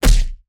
face_hit_Large_20.wav